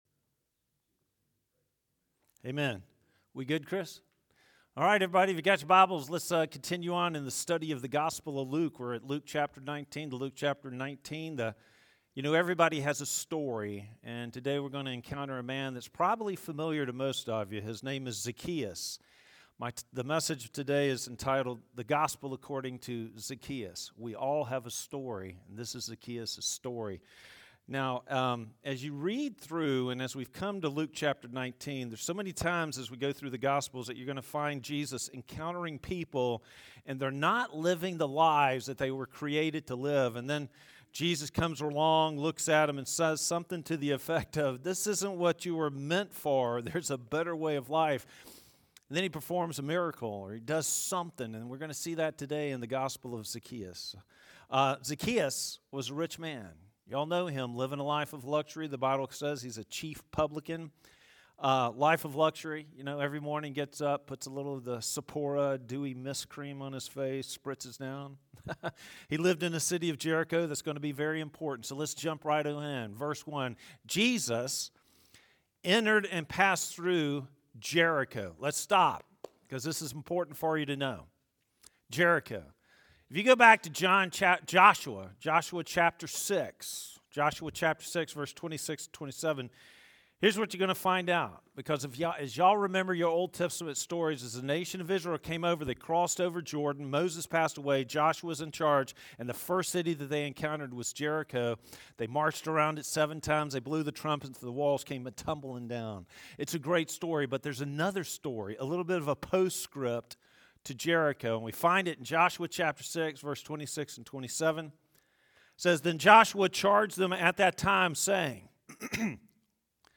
Sermon Video & Audio